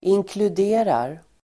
Uttal: [inklud'e:rar]